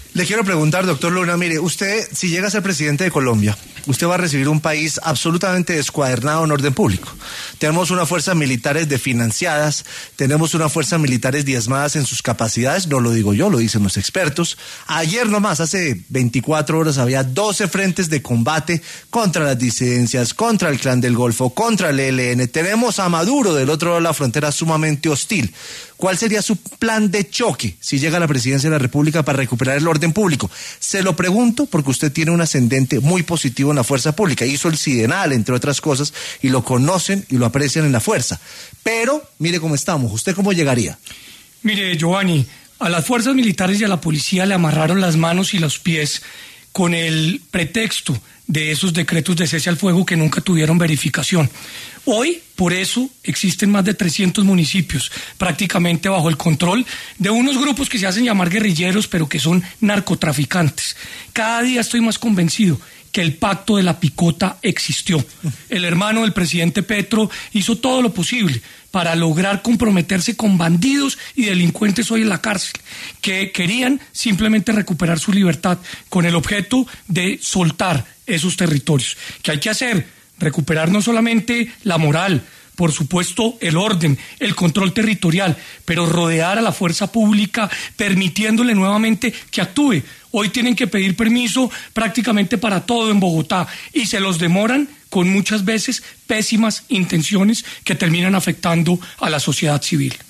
David Luna, exsenador con aspiraciones presidenciales, habló en ‘Sin Anestesia’ de su convencimiento de la existencia del denominado ‘Pacto de la Picota’.
David Luna, exsenador y aspirante a la presidencia de Colombia, pasó por los micrófonos de ‘Sin Anestesia’, de Caracol Radio y Canal 1, para discutir sobre una llegada hipotética a la Casa de Nariño.